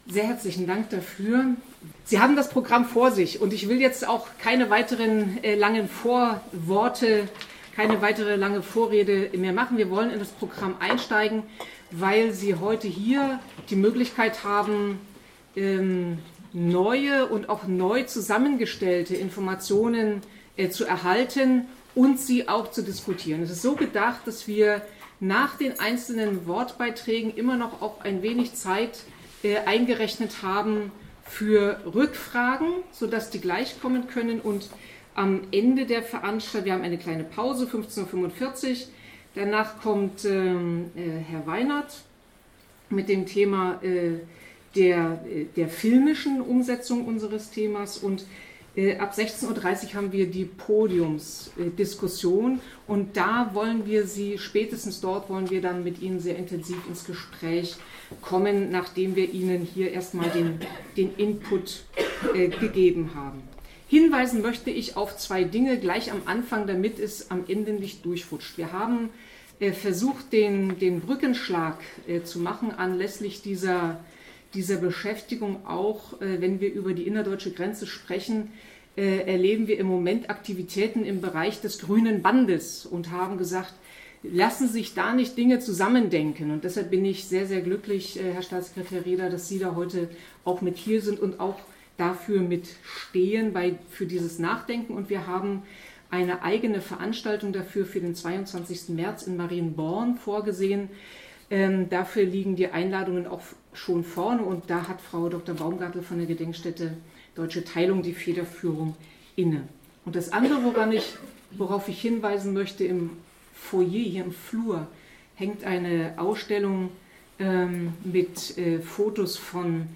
Dokumentation der Fachveranstaltung im Magdeburger Landtag 28.2.2018: „Die Todesopfer des DDR-Grenzregimes an der innerdeutschen Grenze 1949–1989 in Sachsen-Anhalt“
Vorstellung